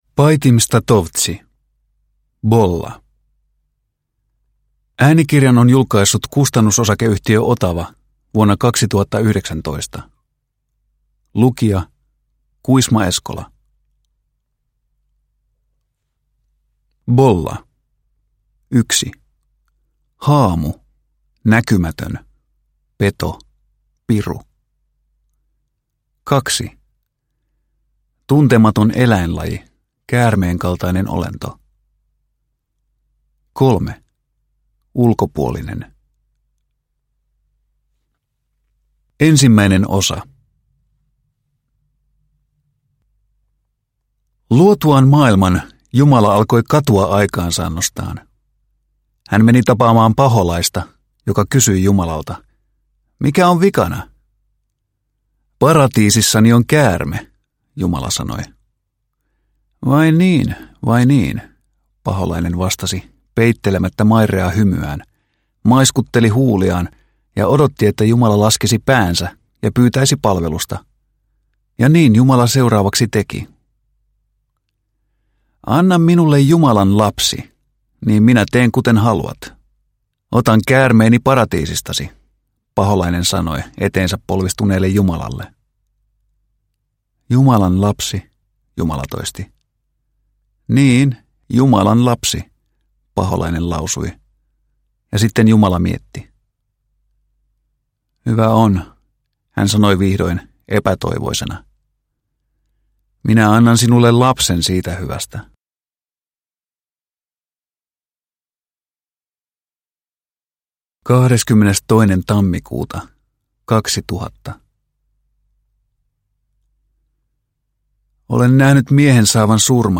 Bolla – Ljudbok – Laddas ner